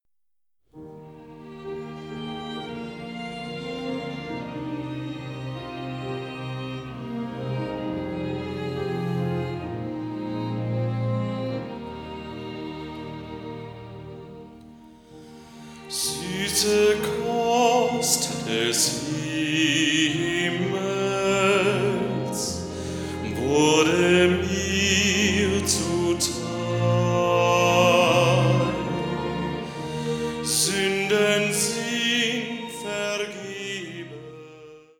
• kurzweilige Zusammenstellung verschiedener Live-Aufnahmen
Solo, Streicher, Klavier